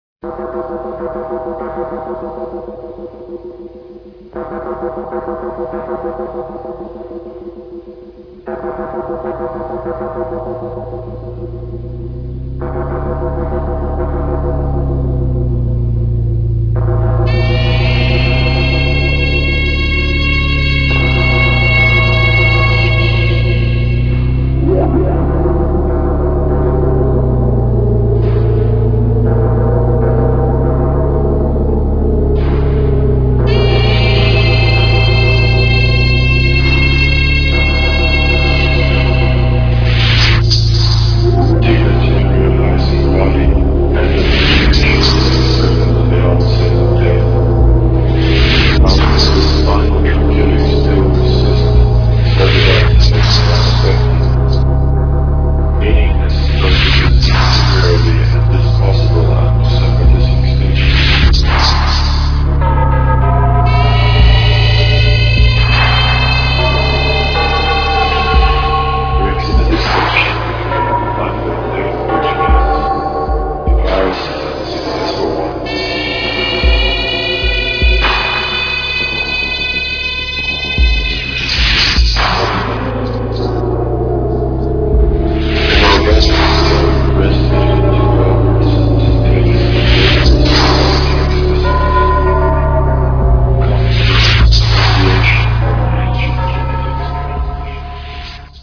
INDUSTRIAL KLACIKO